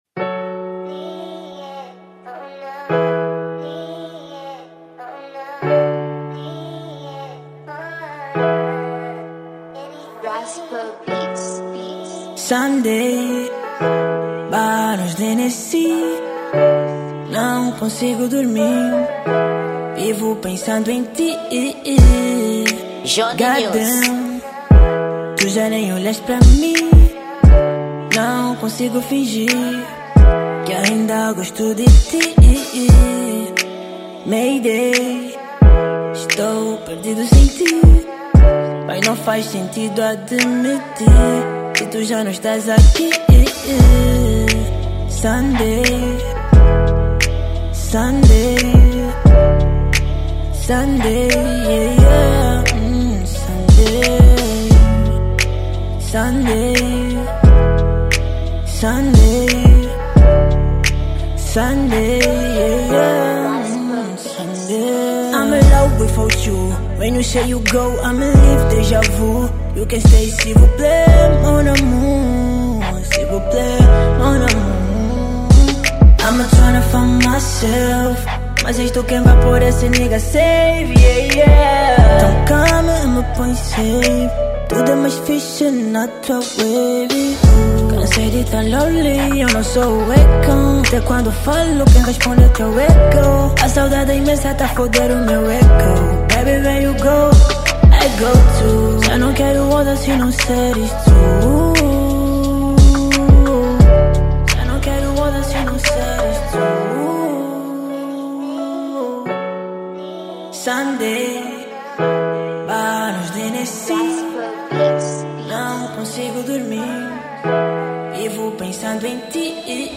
Gênero: R&B